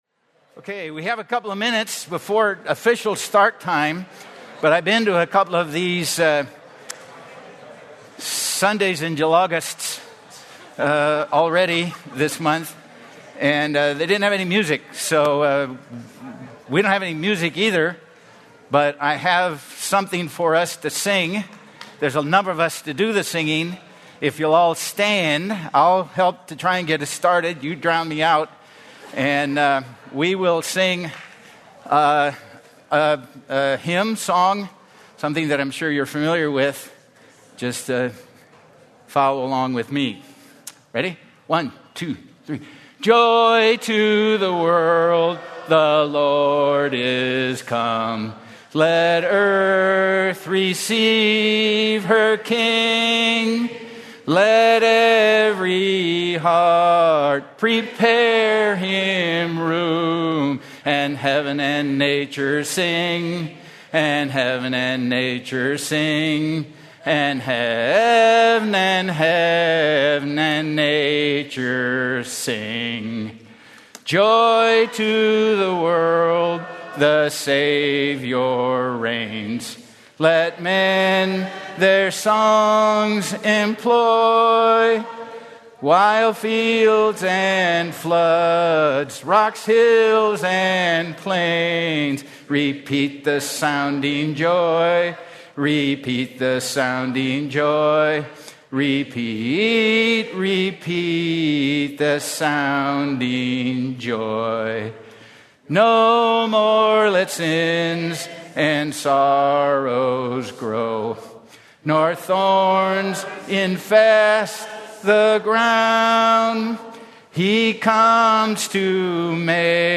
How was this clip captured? Recent sermons from Sundays in July, a ministry of Grace Community Church in Sun Valley, California.